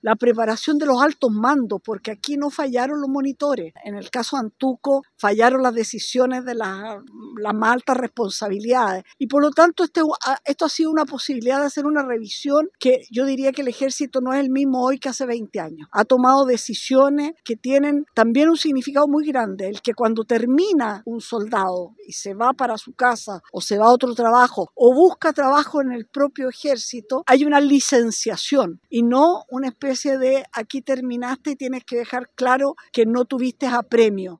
La ministra de Defensa, Adriana Del Piano, señaló que tras lo ocurrido hace 20 años, se sacaron lecciones importantes, pero no solo con los conscriptos, sino también con los mandos de la institución en materia de preparación.